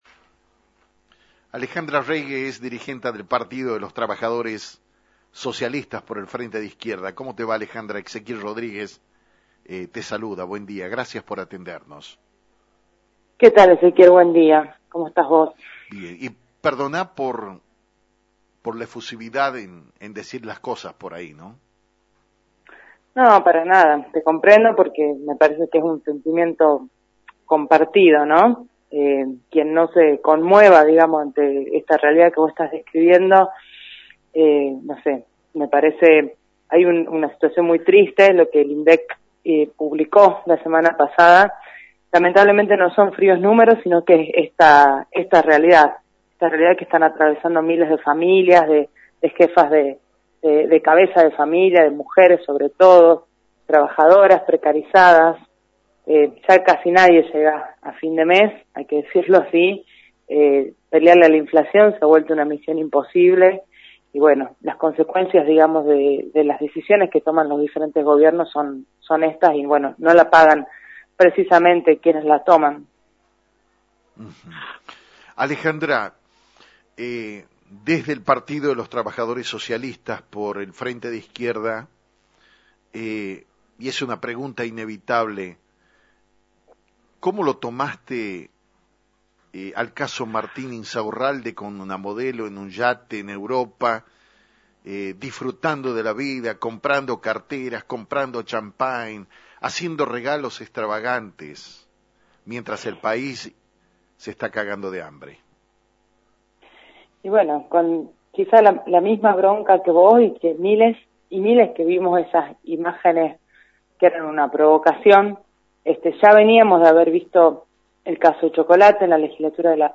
En diálogo con Actualidad en Metro